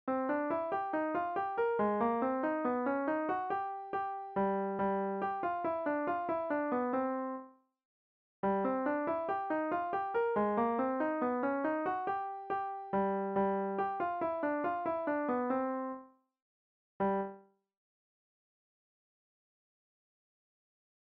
Look for the upbeat on the second repeat, to start and forward motion the line anew.
Catch the pentatonic motion in bars one and two?